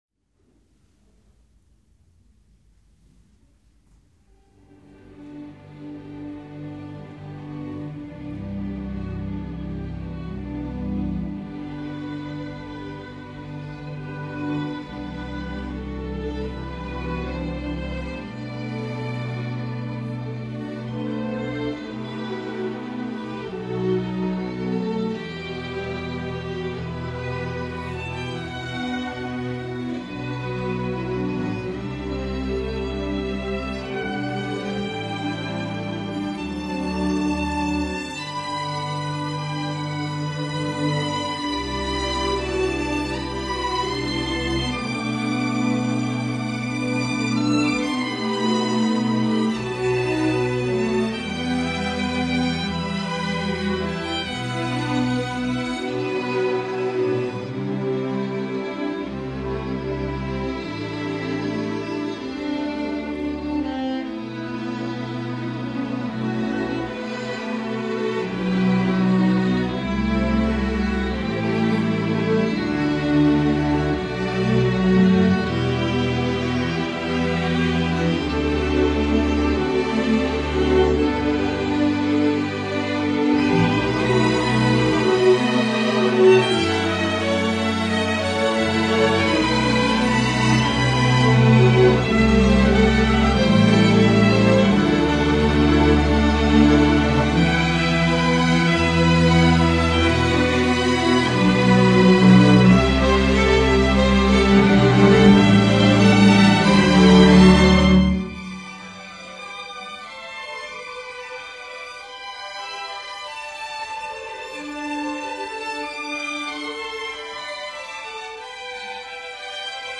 для камерного оркестру.